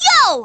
One of Princess Daisy's voice clips in Mario Kart: Double Dash!!